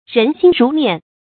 注音：ㄖㄣˊ ㄒㄧㄣ ㄖㄨˊ ㄇㄧㄢˋ
人心如面的讀法